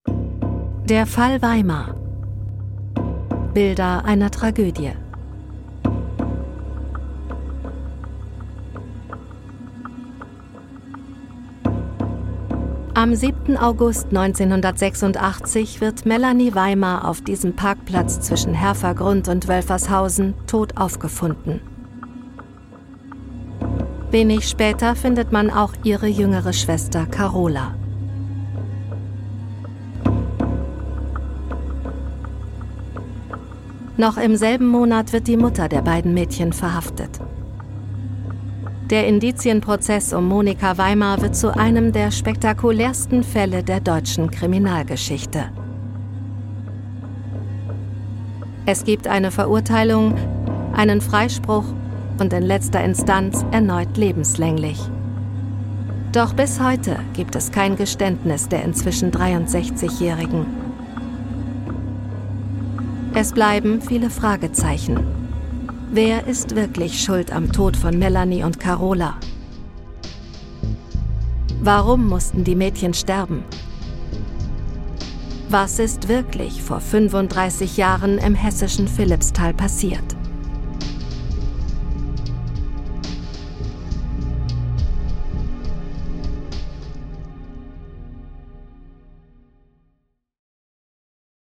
sehr variabel
Doku, Comment (Kommentar)